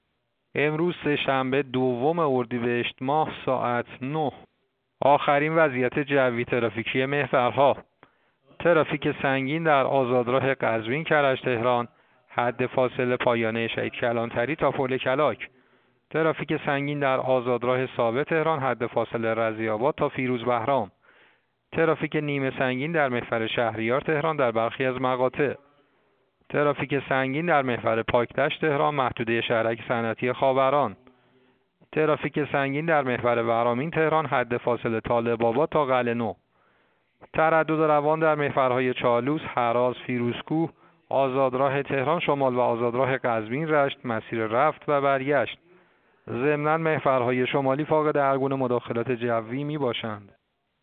گزارش رادیو اینترنتی از آخرین وضعیت ترافیکی جاده‌ها ساعت ۹ دوم اردیبهشت؛